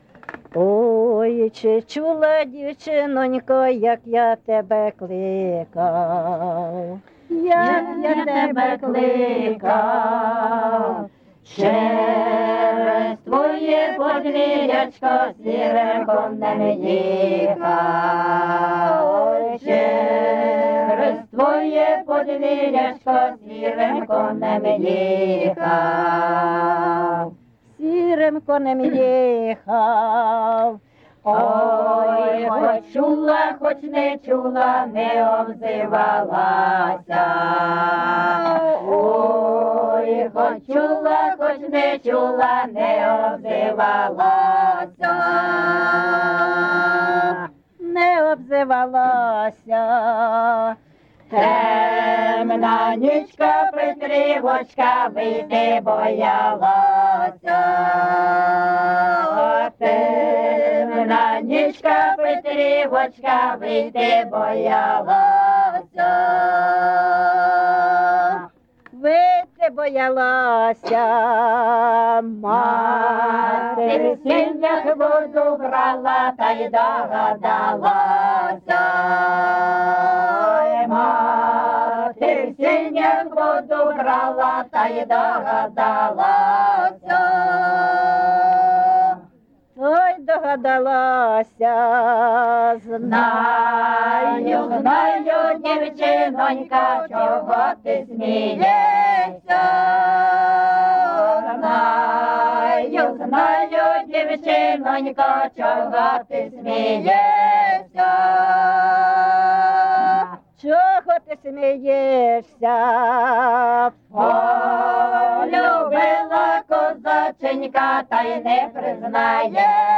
ЖанрПісні з особистого та родинного життя
Місце записус. Шарівка, Валківський район, Харківська обл., Україна, Слобожанщина